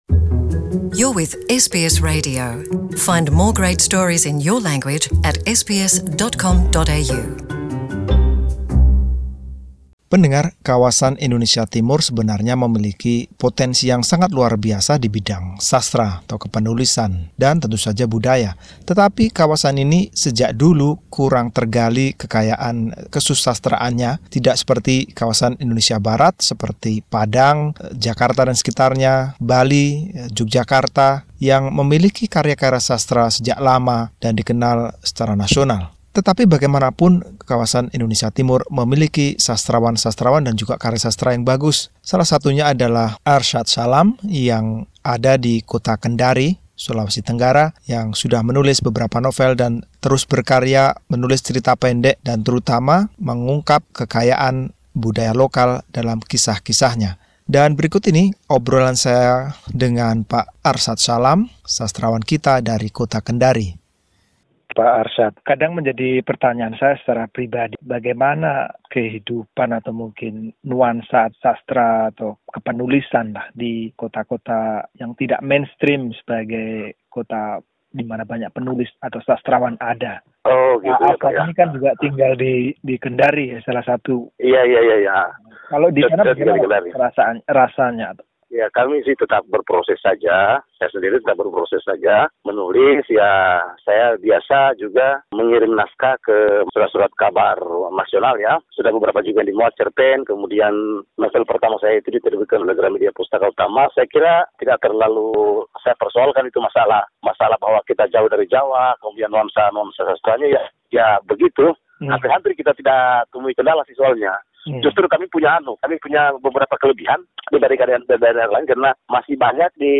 Wawancara